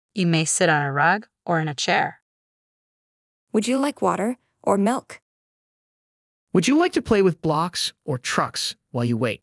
Select the audio clip to hear examples of teachers providing choices to children.
PROVIDING_CHOICES_MIX.mp3